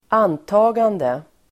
Uttal: [²'an:ta:gande]